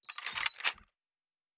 pgs/Assets/Audio/Sci-Fi Sounds/Weapons/Weapon 10 Reload 3.wav at 7452e70b8c5ad2f7daae623e1a952eb18c9caab4
Weapon 10 Reload 3.wav